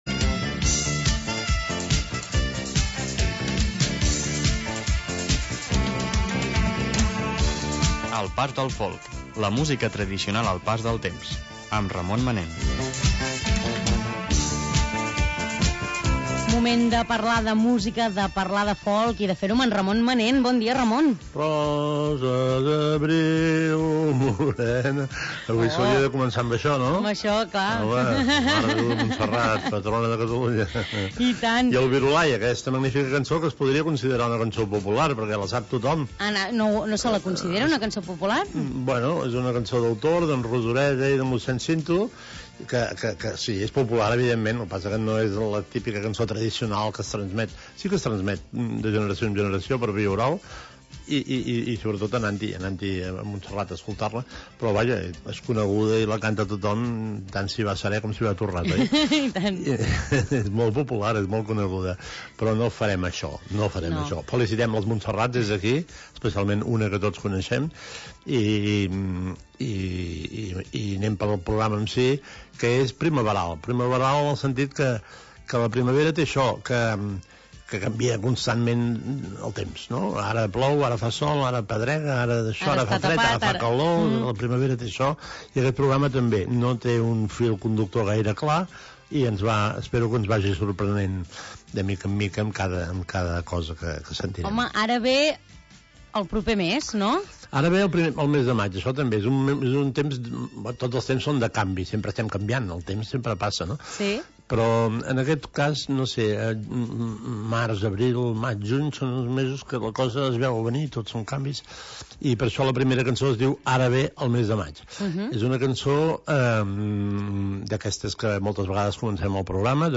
espai de música tradicional